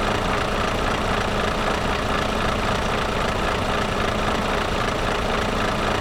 Index of /server/sound/vehicles/lwcars/truck_daf_xfeuro6
idle.wav